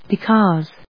音節be・cause 発音記号・読み方
/bɪkˈɔːz(米国英語), bɪˈkɒz(英国英語)/